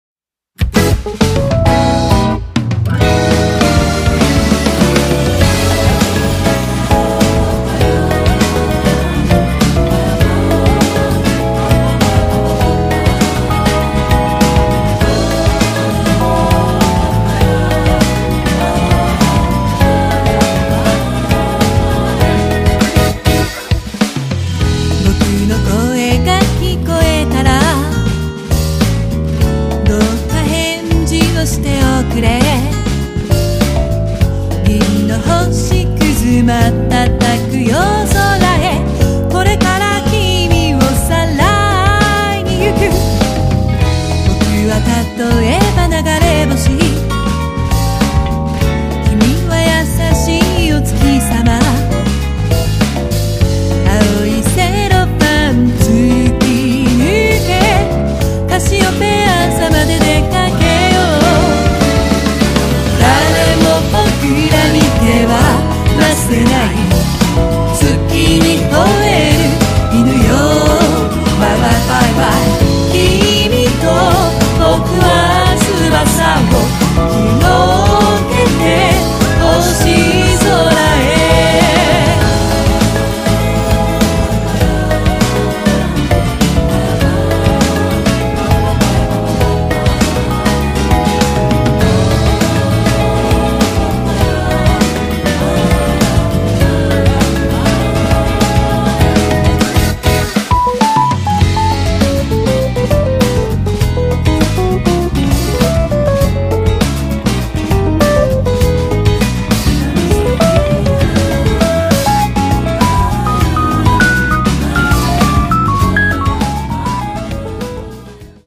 ジャンル(スタイル) JAPANESE POP / CITY POP